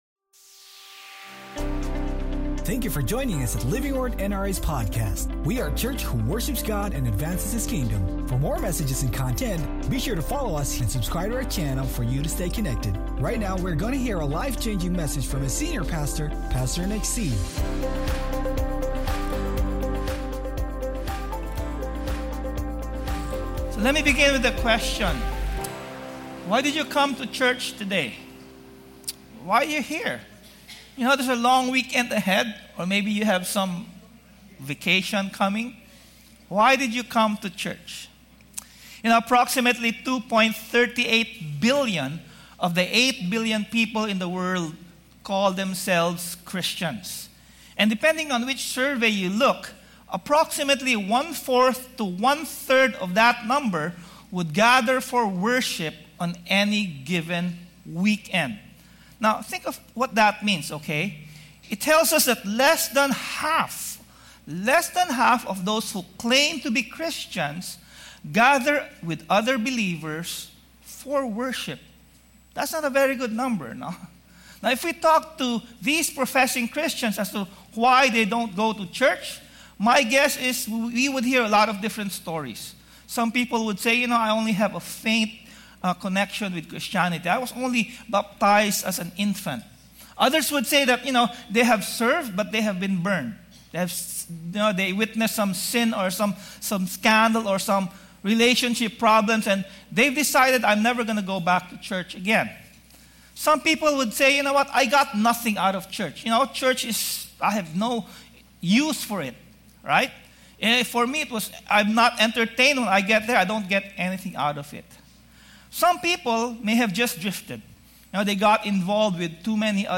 Sermon Title: CHURCH MATTERS